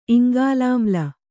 ♪ iŋgalāmla